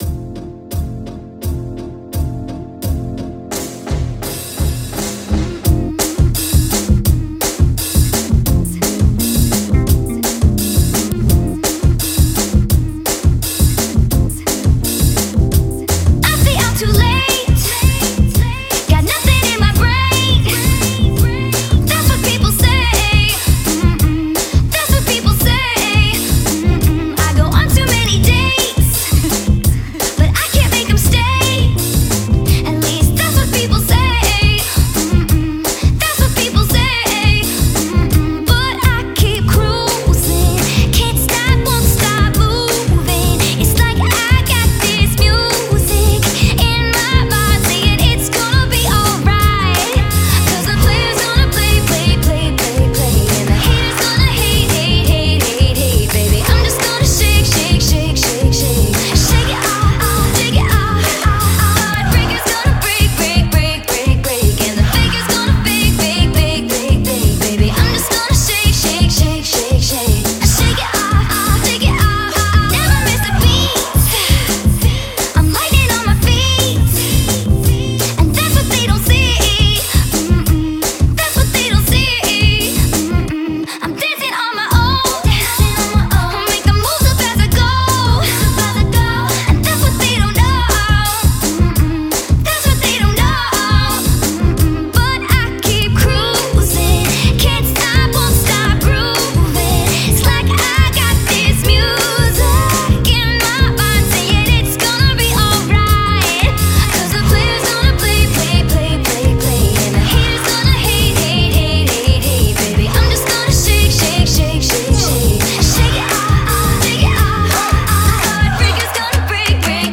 Bootleg mashup tracks featuring pop, dance and chart songs